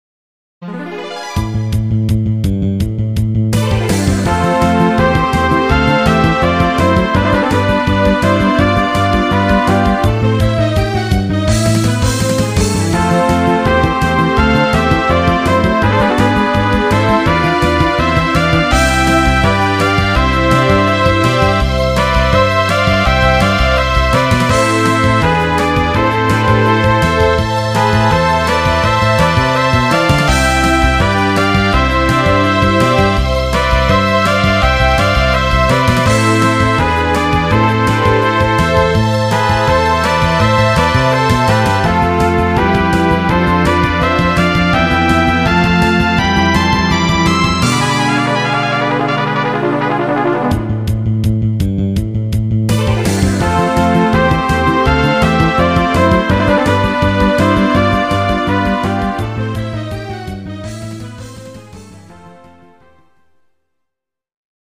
GS音源。